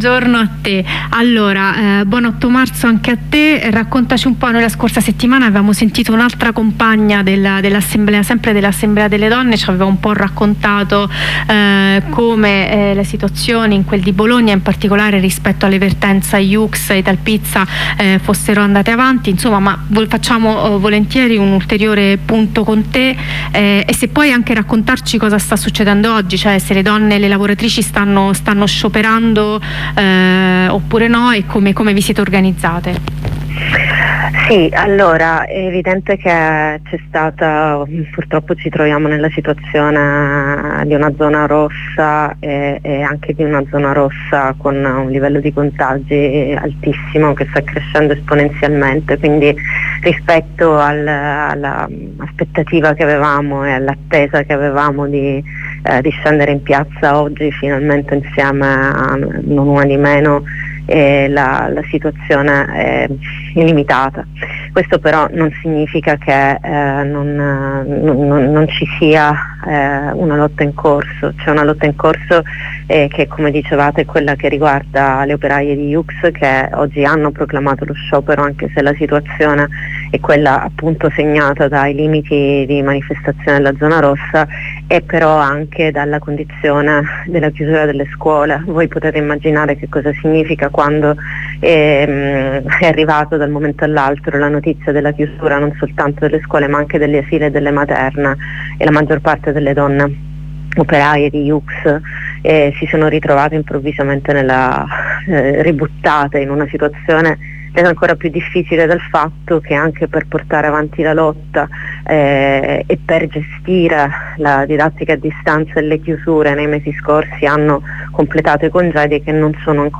Oggi scioperano le operaie di Yoox, da mesi in lotta contro l’azienda di moda che impone turni difficili alle lavoratrici madri, "costringendole a fare i salti mortali per potersi occupare di figlie e figli". Ne parliamo con una lavoratrice e compagna della Yoox e con una compagna dell'assemblea donne del coordinamento migranti di Bologna.